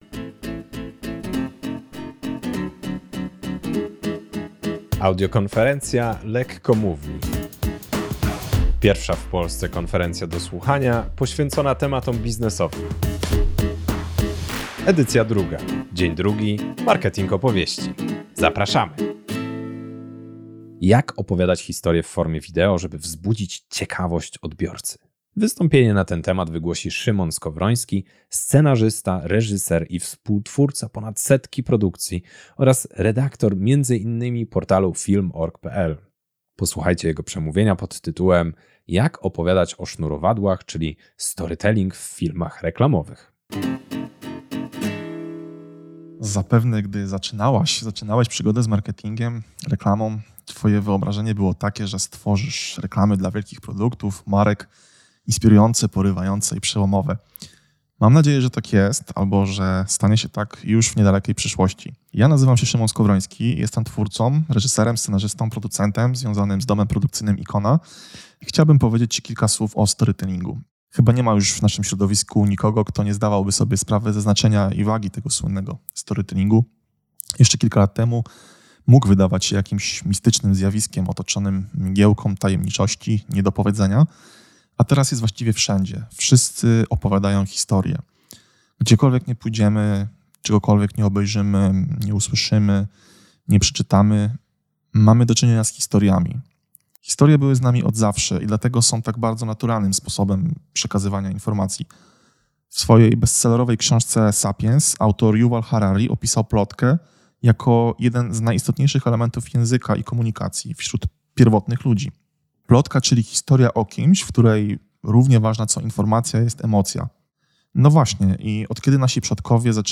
Wystąpienie w ramach Audiokonferencji Lekkomówni x Marketing Opowieści.